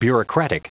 Prononciation audio / Fichier audio de BUREAUCRATIC en anglais
Prononciation du mot : bureaucratic